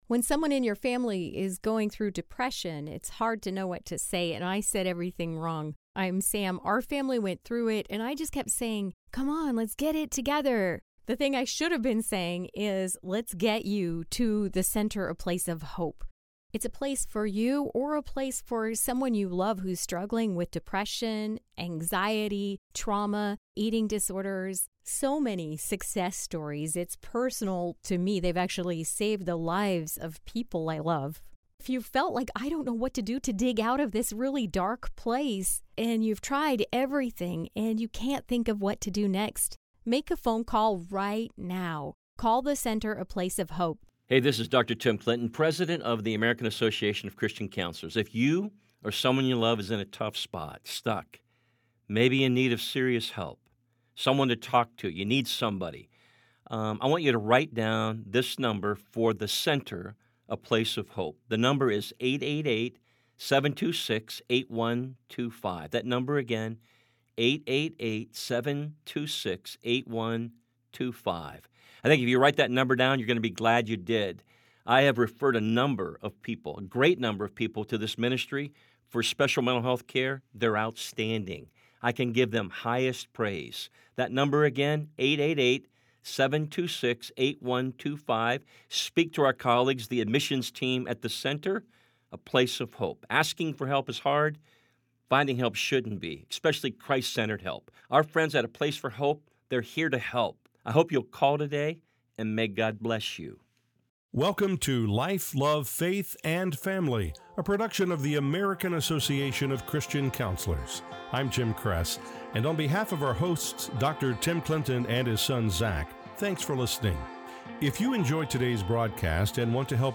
Together, they explore how to break free from toxic cycles and overcome shame. She explains that addiction often stems from deeper emotional wounds. If you’re struggling with addiction or supporting someone who is, this discussion offers hope, wisdom, and a powerful reminder that healing is possible when you surrender to God daily.